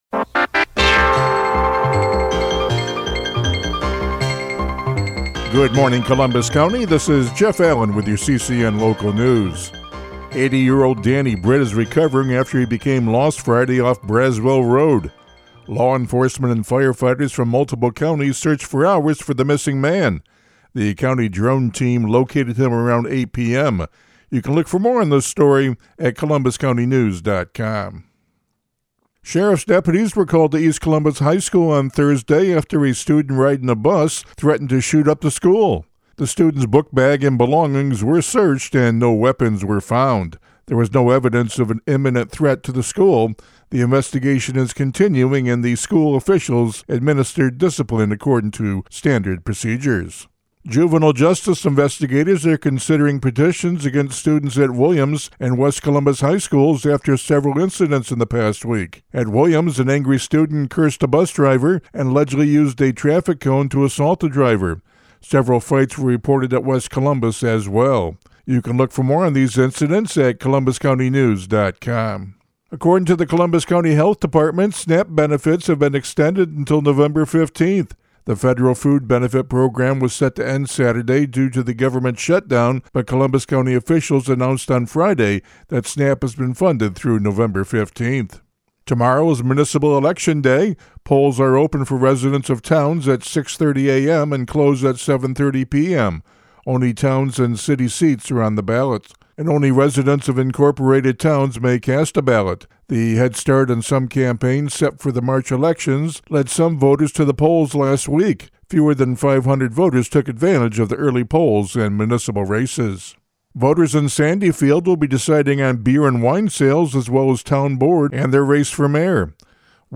CCN Radio News — Morning Report for November 3, 2025
CCN-MORNING-NEWS.mp3